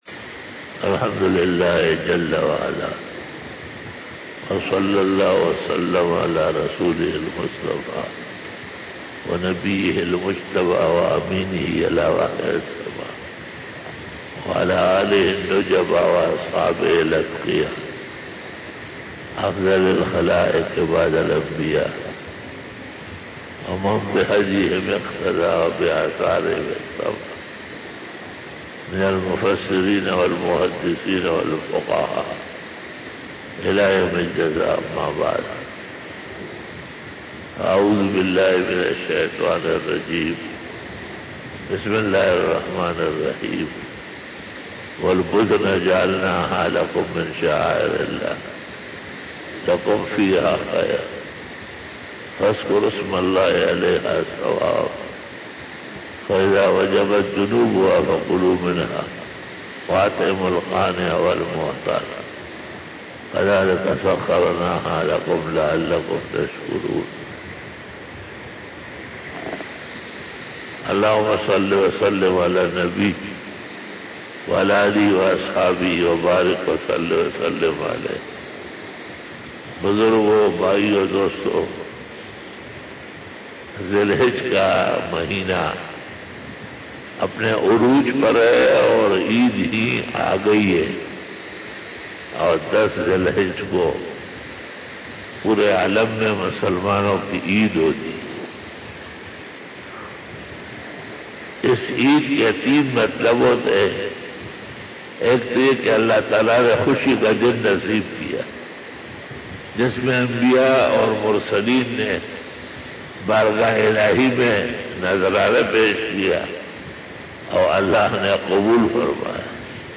Khitab-e-Jummah